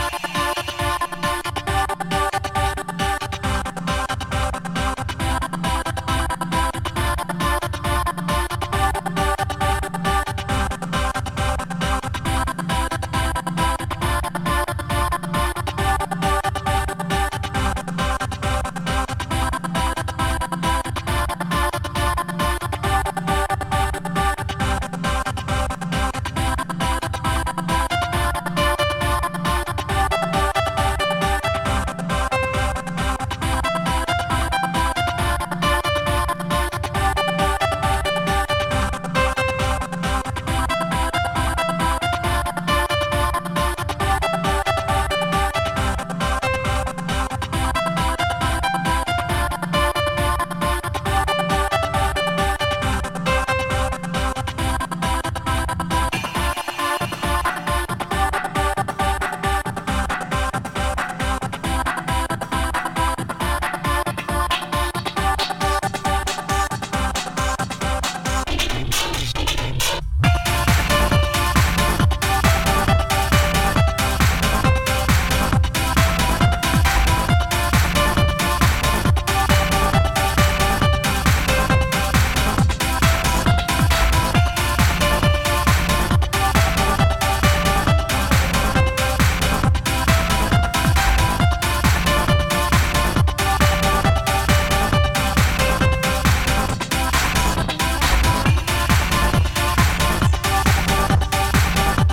悲し気なシンセフレーズが印象的なトランシー・テック